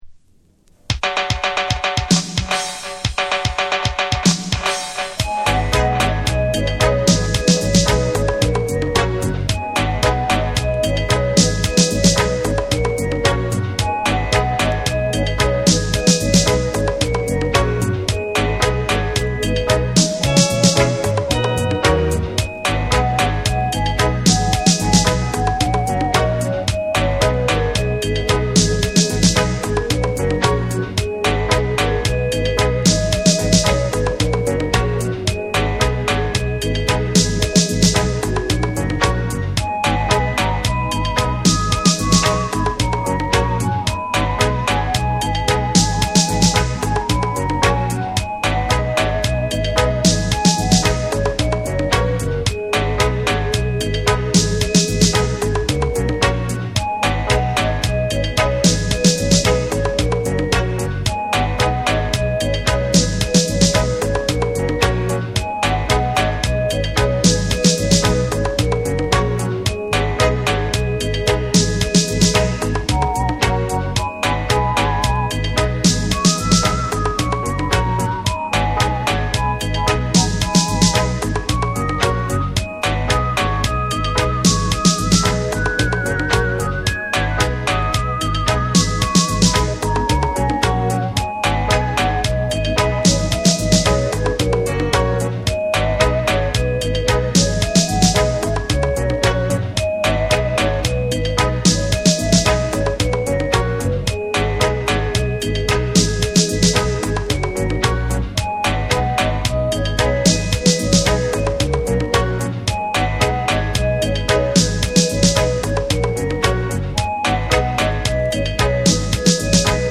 重厚なベースとクリアなミキシングが際立つ、UKルーツ〜ダブ好盤。
REGGAE & DUB